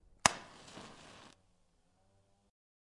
照明匹配
描述：用火柴盒点燃的比赛。噼里啪啦的声音随之而来。